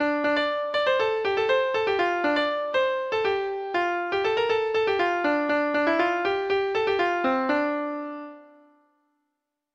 Traditional Trad. King Henry Treble Clef Instrument version
Folk Songs from 'Digital Tradition' Letter K King Henry